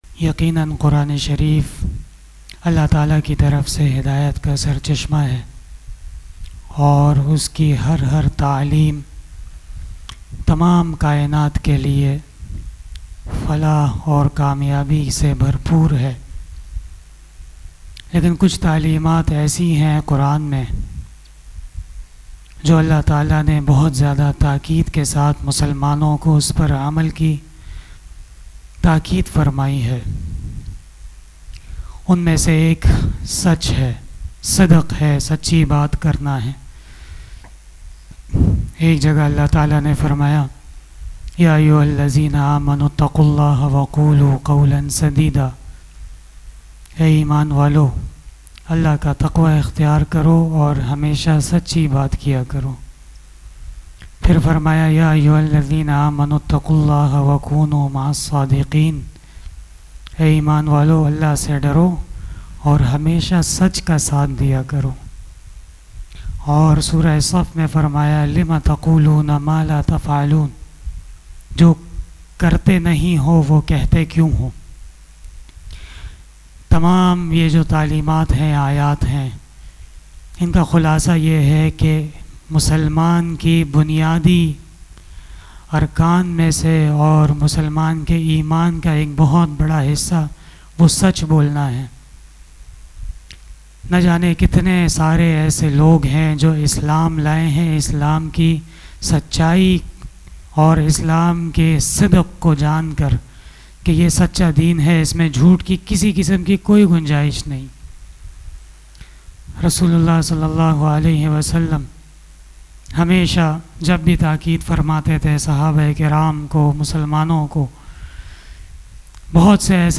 020 After Fajar Namaz Bayan 09 May 2021 ( 26 Ramadan 1442HJ) Sunday